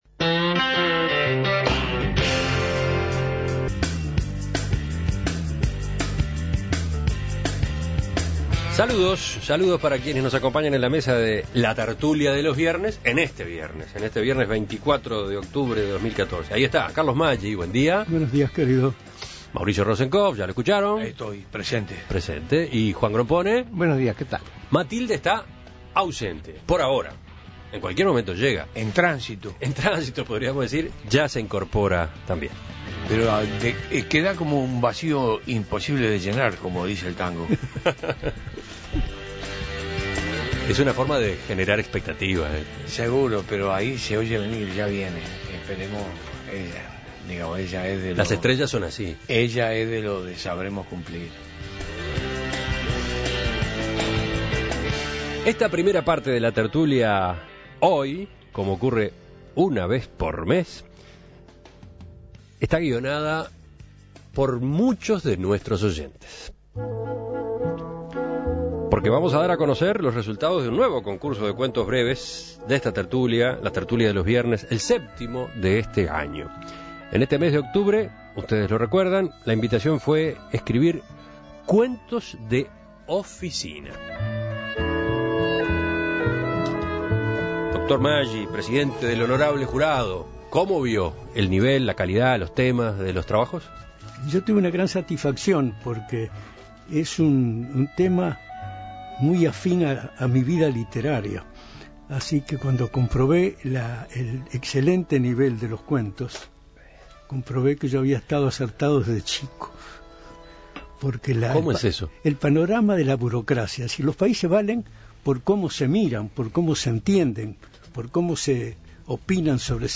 Cuentos leídos por los contertulios en esta séptima edición del concurso de cuentos breves de la Tertulia de los Viernes